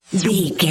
Bright Implode
Sound Effects
Atonal
funny
magical
mystical